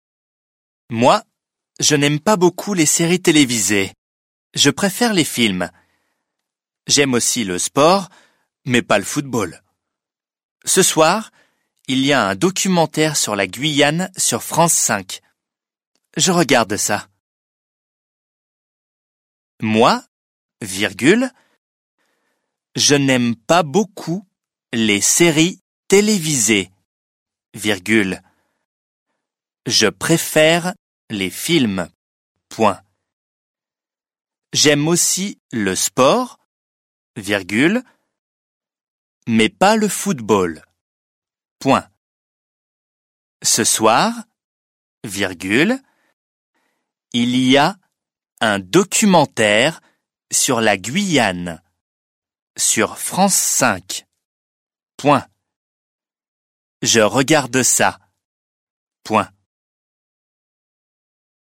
دیکته - مبتدی